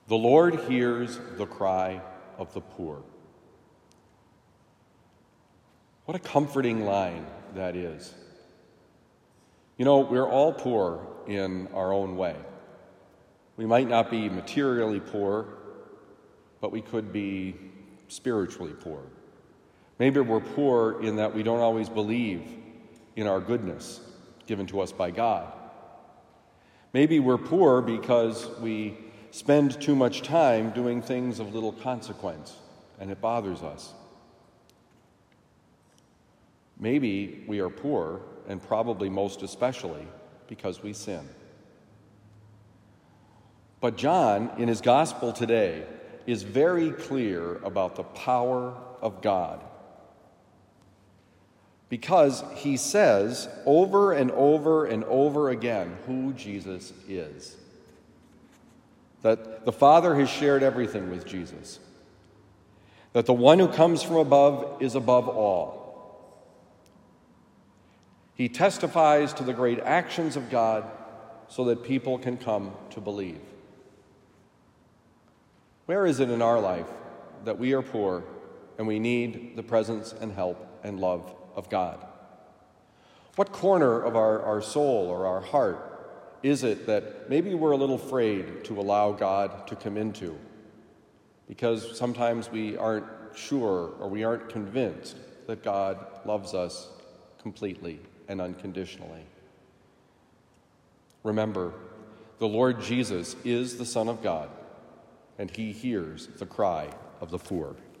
The Lord Hears the Cry of the Poor: Homily for Thursday, April 20, 2023
Given at Christian Brothers College High School, Town and Country, Missouri.